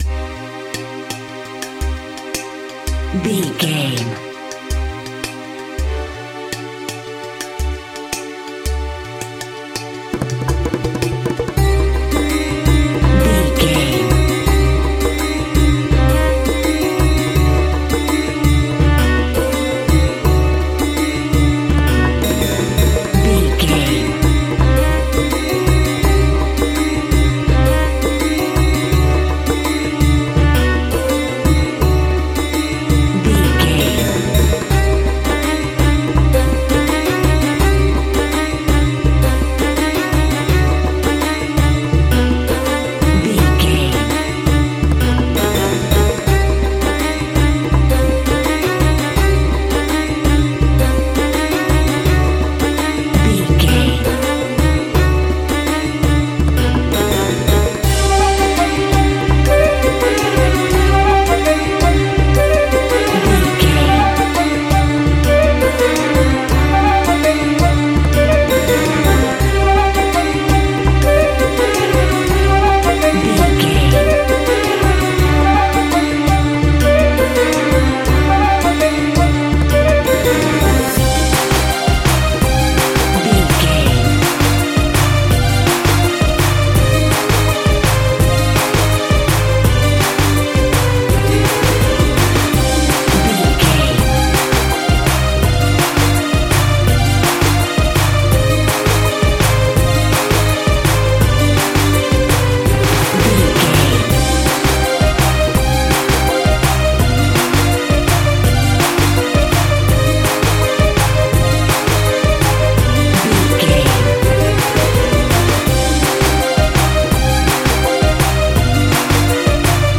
Aeolian/Minor
Fast
World Music
percussion